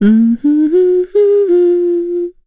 Yuria-Vox_Hum_kr.wav